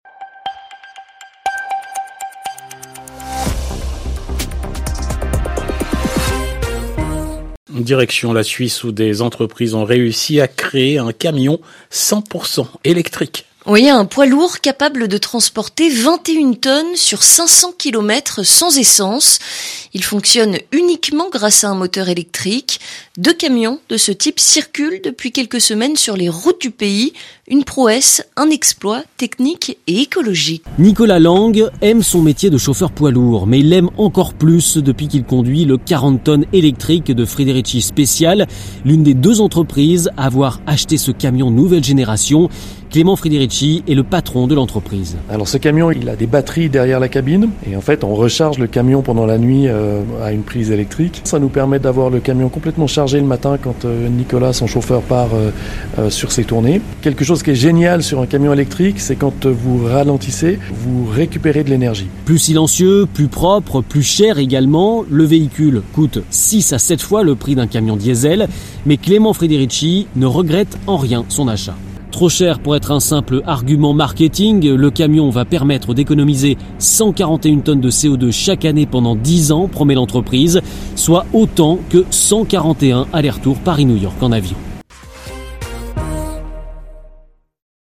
Extrait du Journal en français facile du 13/12/2021 (RFI)